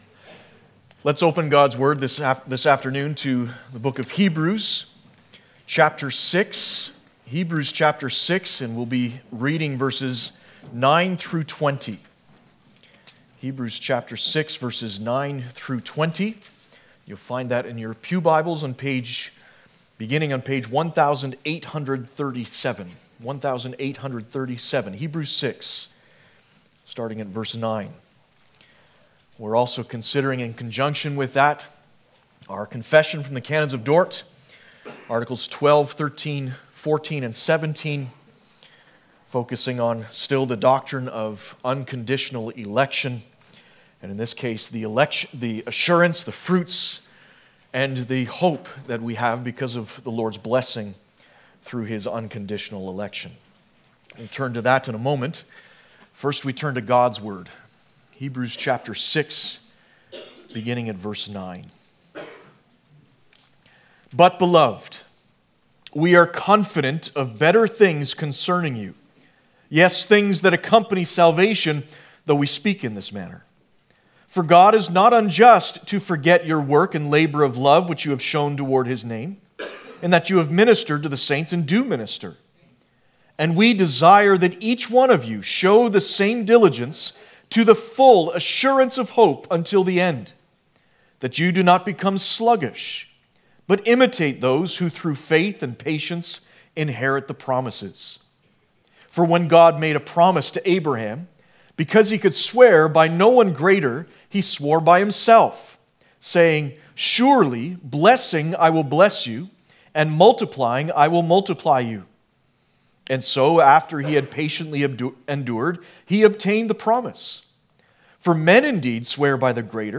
Canons of Dordt Passage: Hebrews 6:9-20, Canons of Dort I.12-14 17 Service Type: Sunday Afternoon « Is the LORD among us or not?